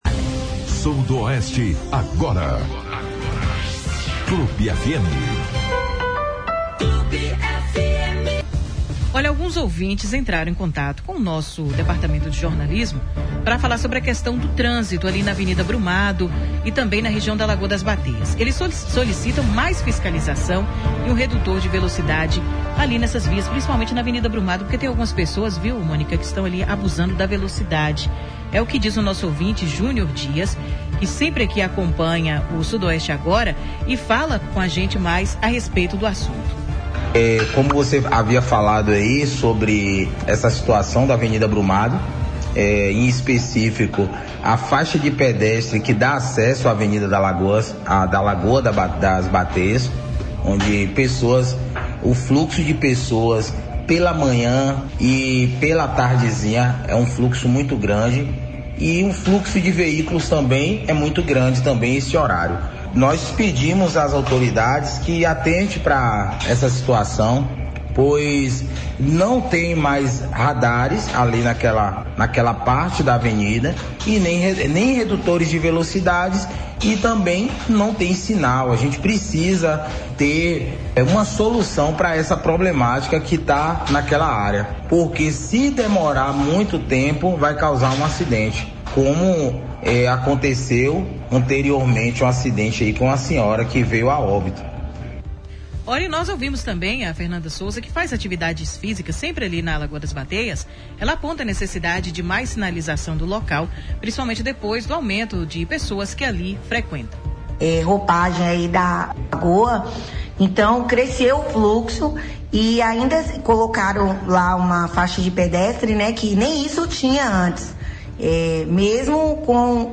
Em entrevista ao programa Sudoeste Agora, na Rádio Clube de Conquista, reproduzida pelo BLOG DO ANDERSON nesta terça-feira (31), o comandante do Policiamento da Região Sudoeste (CPR-Sudoeste), Coronel Paulo Henrique Rocha Guimarães, detalhou os motivos que levaram à interrupção do modelo anterior. Segundo o comandante, a unidade operava sem o devido Termo de Cooperação Técnica firmado com o Comando Geral da PMBA, condição indispensável para que qualquer instituição utilize a chancela e o sistema de ensino dos Colégios da Polícia Militar (Vetor CPM).